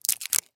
Звуки наклеек
Звук: наклейка слегка отошла от упаковки